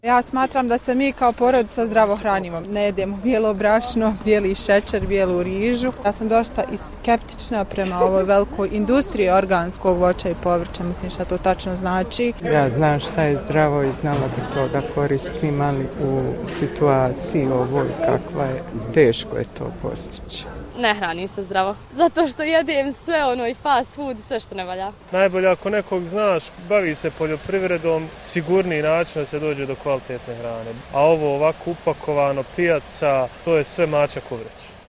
Sarajlije o kvaliteti ishrane